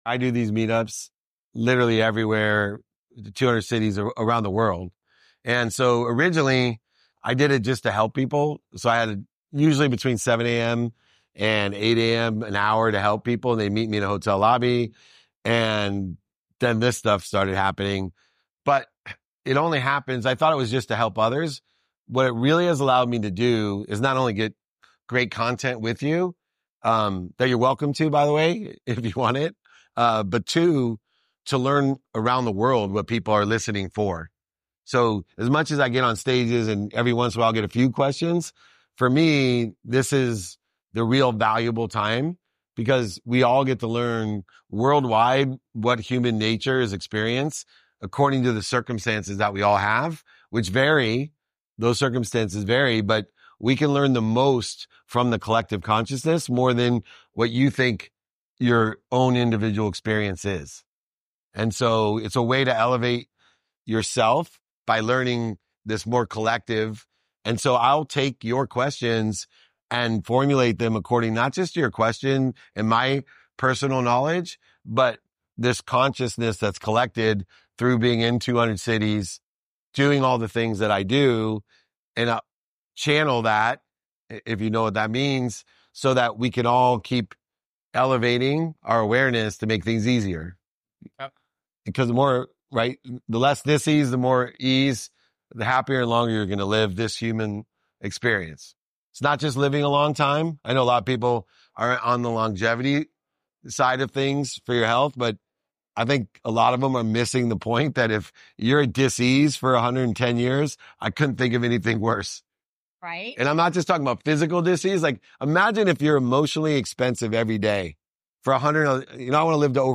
Today's episode captures a live Q&A session in Atlanta, where I share how I calculate emotional expense to quantify the true cost of worry and stress. I explain how aligning intuition with practical decision-making can elevate both personal and professional outcomes, and why non-negotiable habits are essential for living with purpose. I also address raising capital with authenticity, managing time as a resource, and navigating relationships that both feed and challenge us.